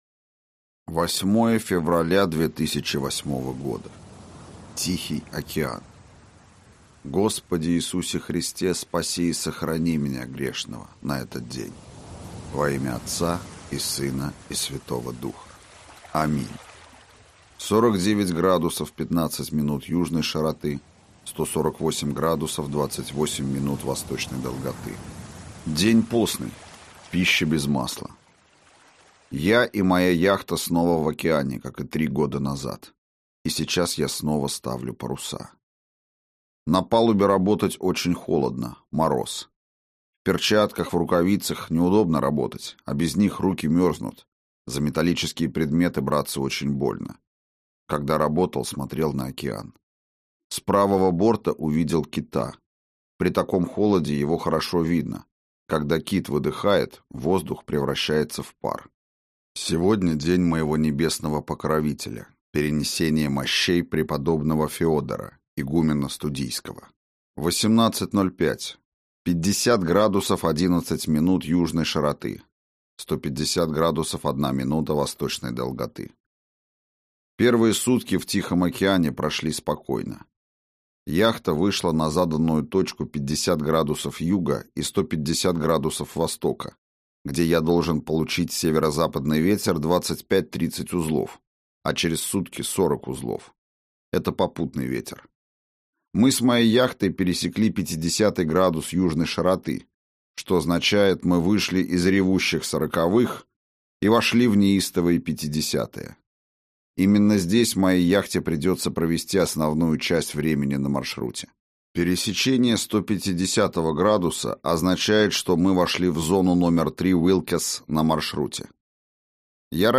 Аудиокнига На грани возможностей | Библиотека аудиокниг